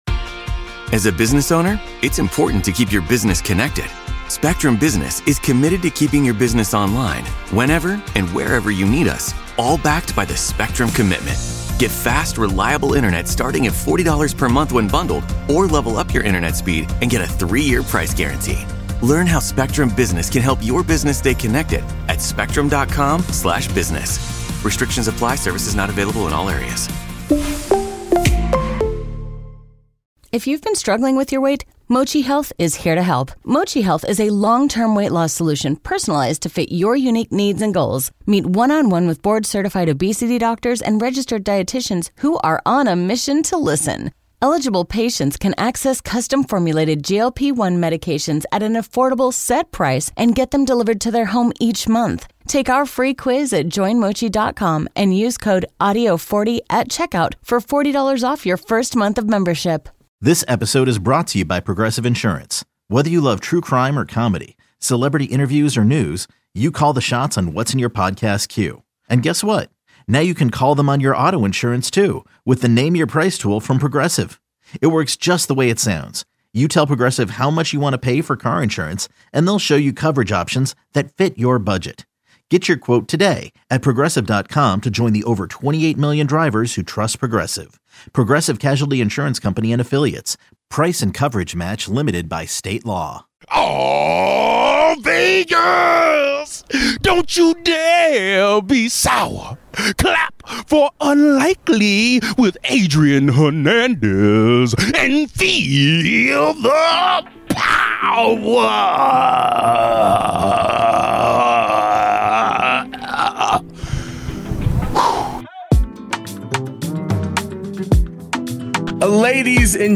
had the chance to speak with WWE Superstar Kevin Owens about the massive event coming to Las Vegas!